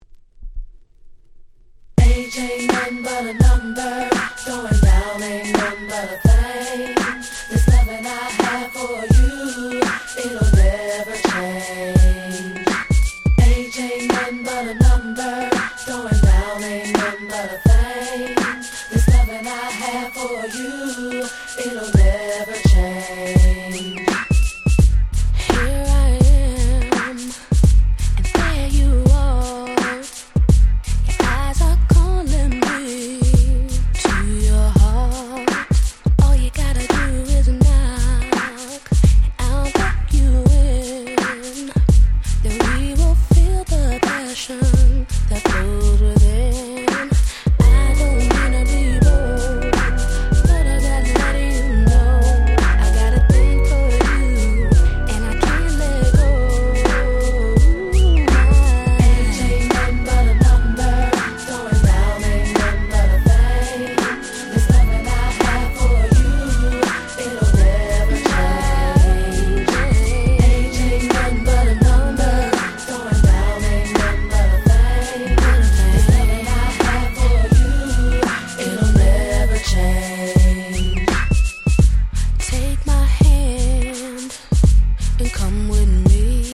94' Smash Hit R&B !!
90's ヒップホップソウル